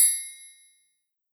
Lex Triangle 3.wav